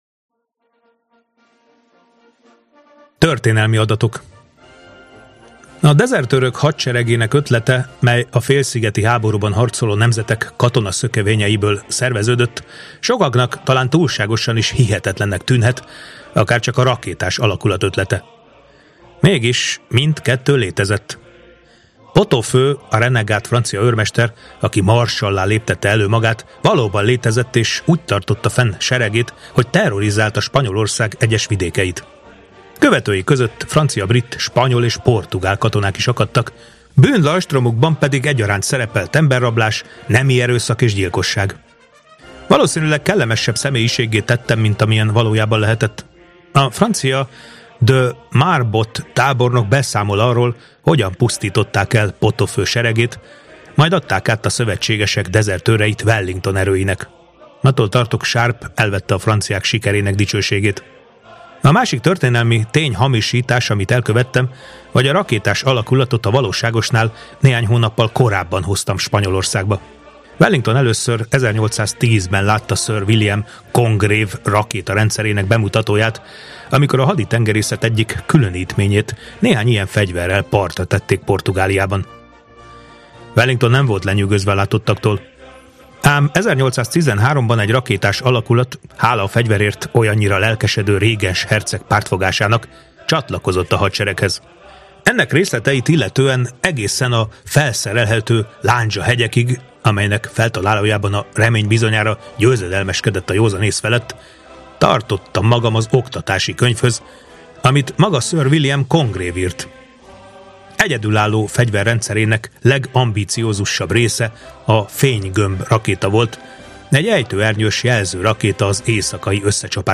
Bernard Cornwell – Sharpe ellensége Hangoskönyv